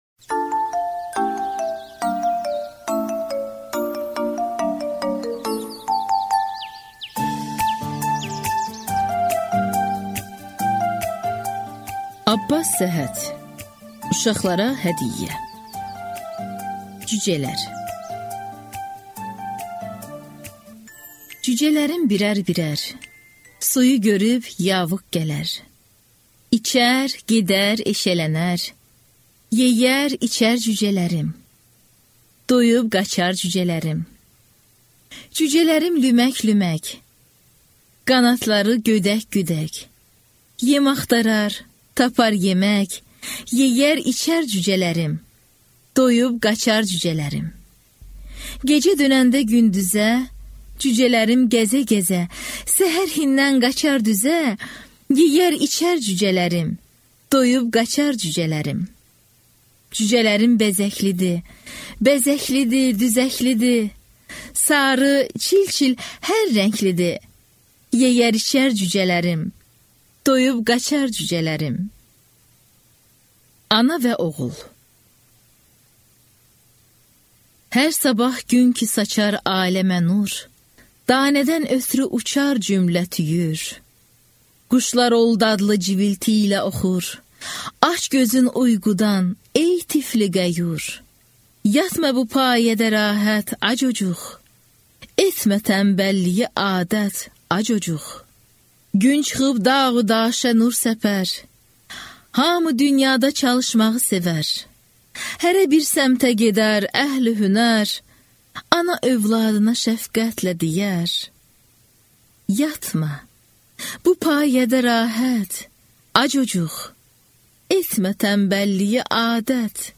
Аудиокнига Uşaqlara hədiyyə A.Səhhət və A.Şaiqin şeirləri | Библиотека аудиокниг